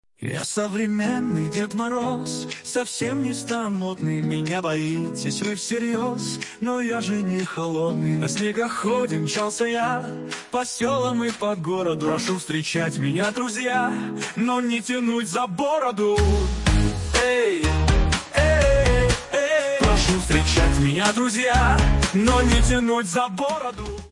Фрагмент исполнения 1 вариант: